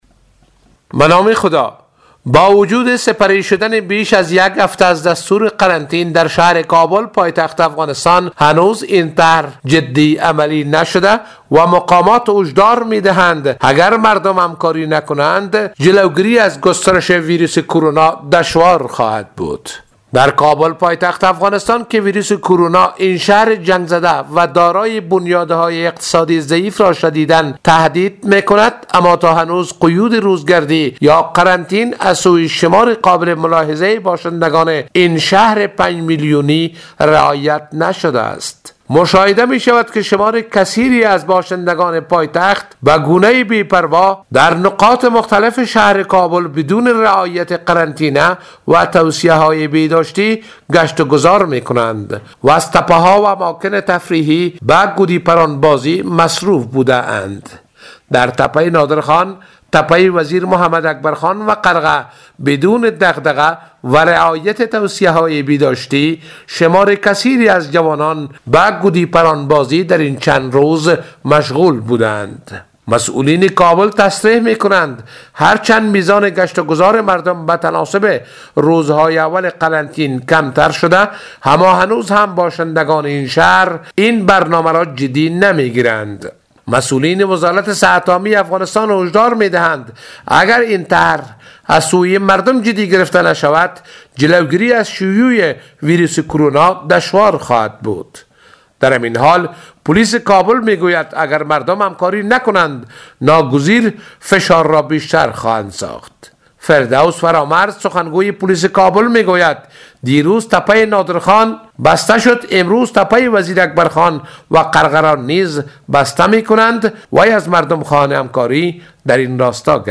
گزارش همکارمان